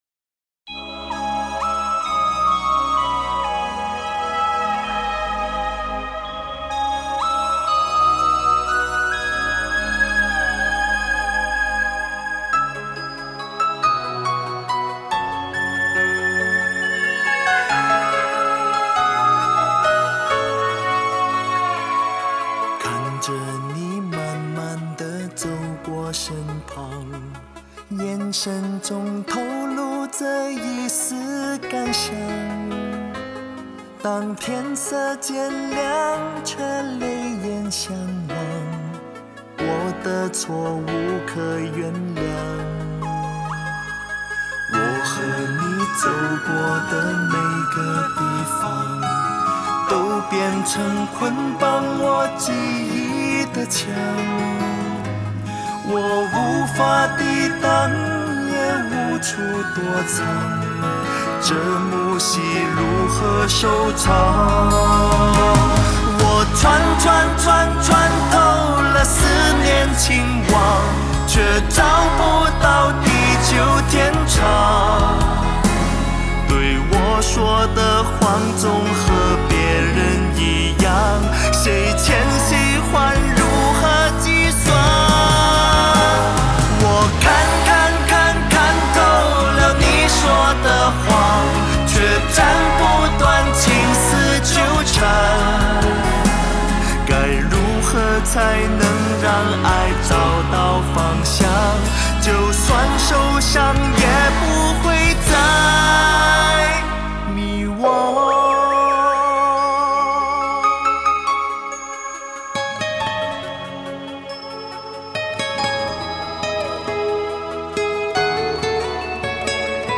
(Live)
国风 收藏 下载